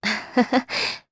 Aselica-Vox_Happy3_jp.wav